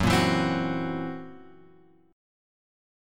F#7b5 chord {2 1 2 x 1 2} chord